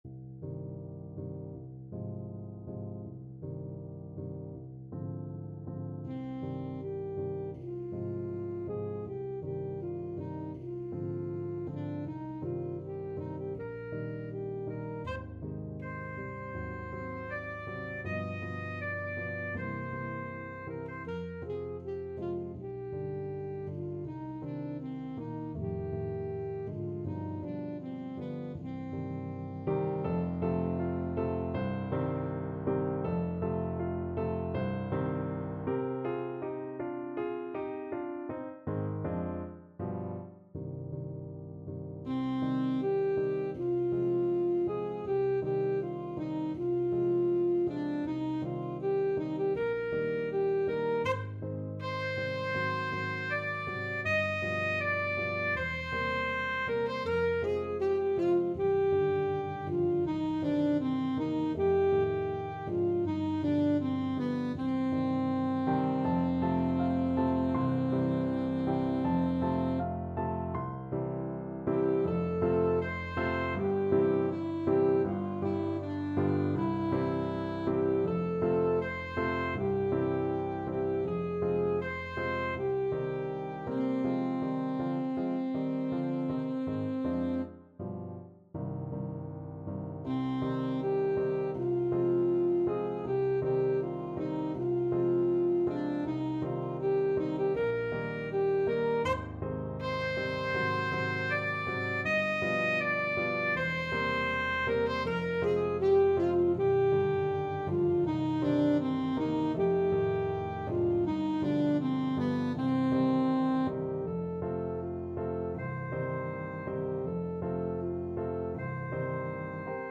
Alto Saxophone
~ = 100 Andante
2/4 (View more 2/4 Music)
Classical (View more Classical Saxophone Music)